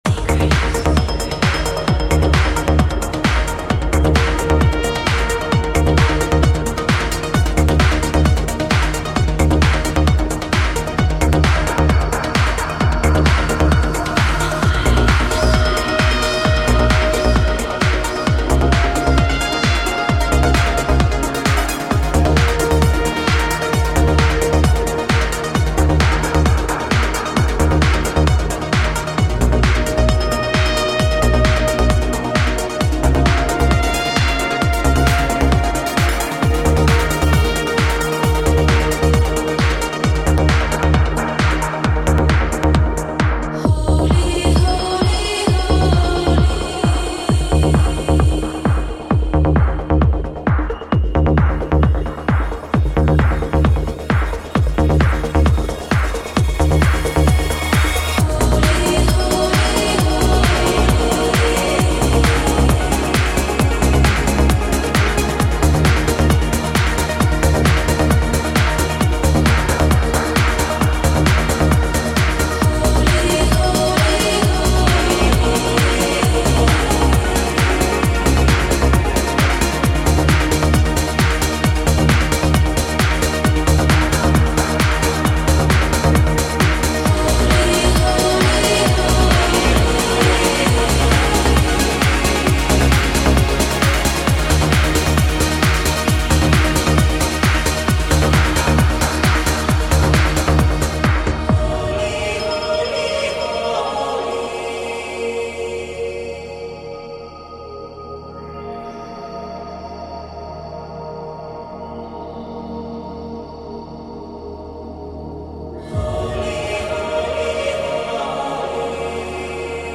*** очень хорошо играет (trance) ***